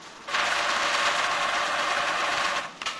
atm_cash.ogg